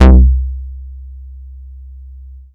VEC1 Bass Long 14 C.wav